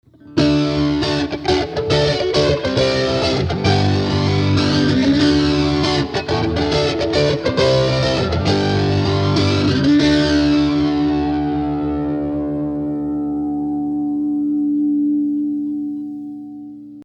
Overdriven with Tube Screamer for Extra Drive (Gibson Nighthawk 2009)
Recording #2 with Graphic EQ, Flange, Delay, Reverb, and Hi-pass filter applied